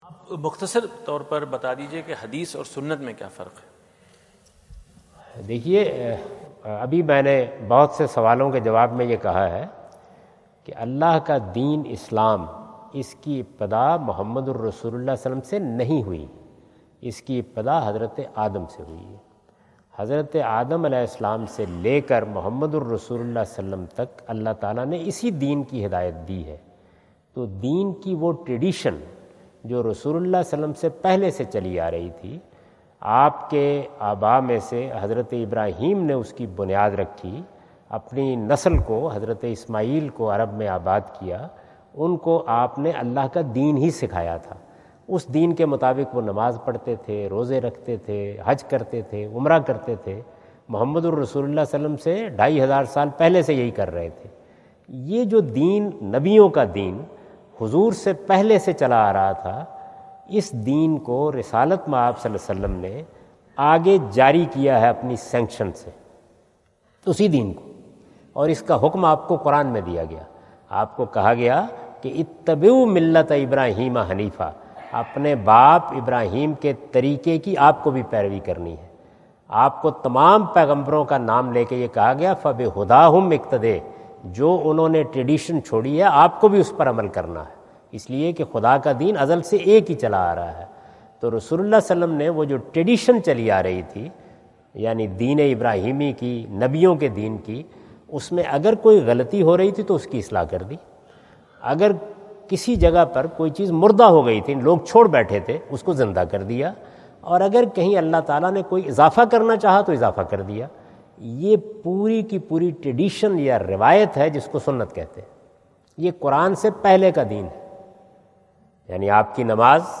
Javed Ahmad Ghamidi answer the question about "Difference between Hadith and Sunnah?" During his US visit at Wentz Concert Hall, Chicago on September 23,2017.